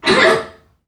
NPC_Creatures_Vocalisations_Robothead [25].wav